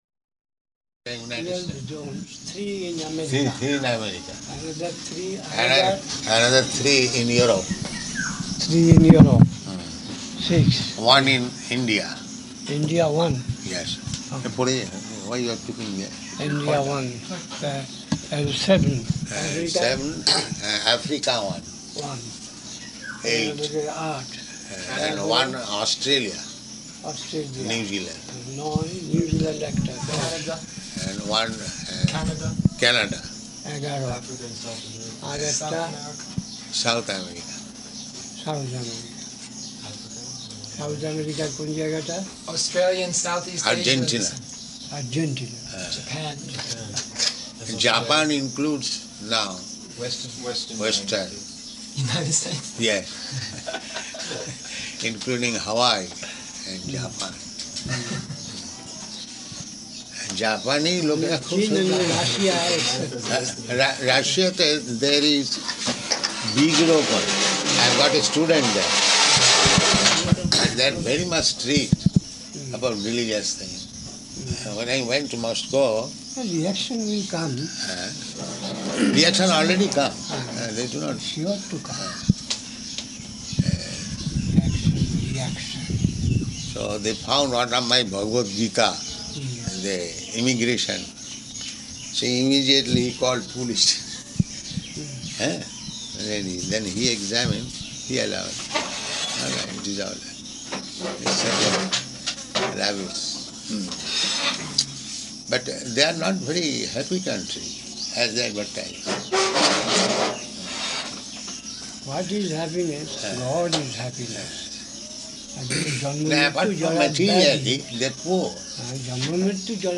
-- Type: Conversation Dated: June 27th 1973 Location: Navadvīpa Audio file